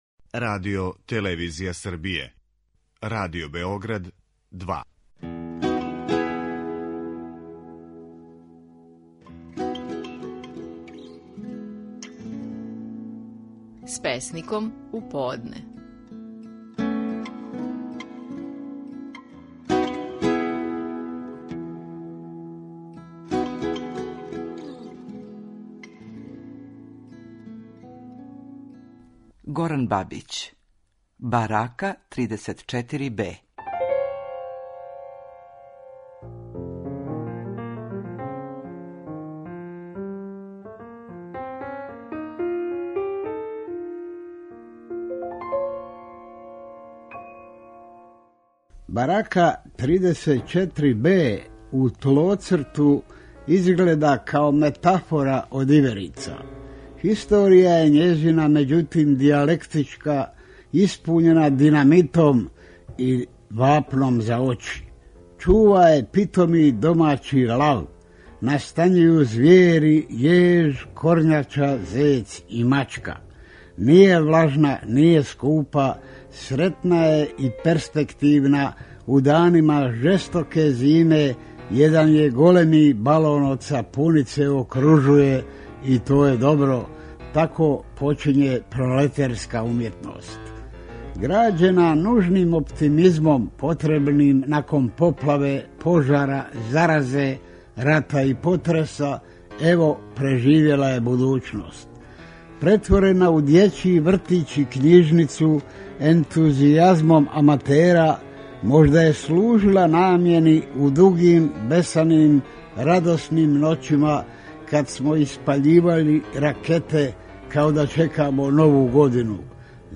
Стихови наших најпознатијих песника, у интерпретацији аутора.
Горан Бабић казује стихове песме „Барака 34-Б".